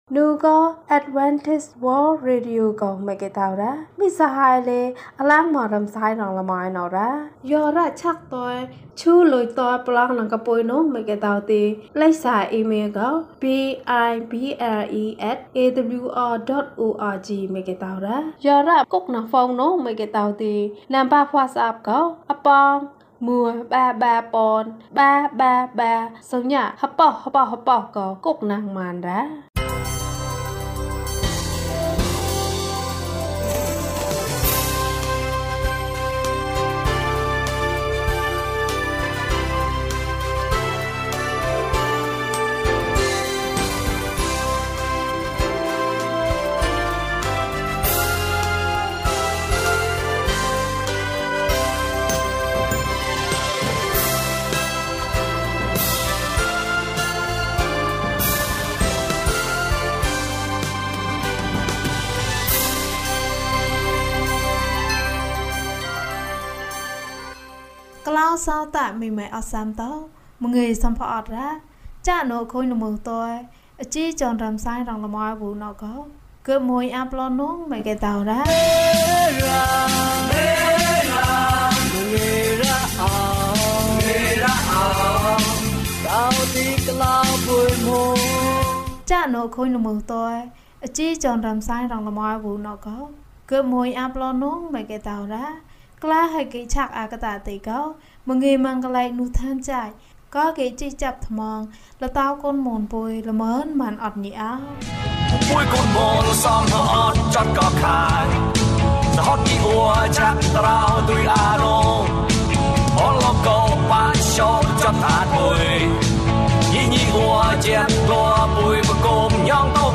ရှင်ဘုရင်။ အပိုင်း ၂ ကျန်းမာခြင်းအကြောင်းအရာ။ ဓမ္မသီချင်း။ တရားဒေသနာ။